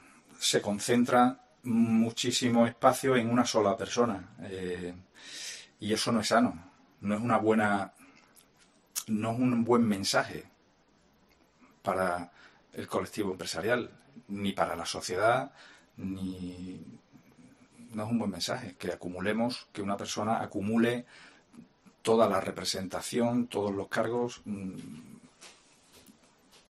acaba de anunciar en rueda de prensa que no seguirá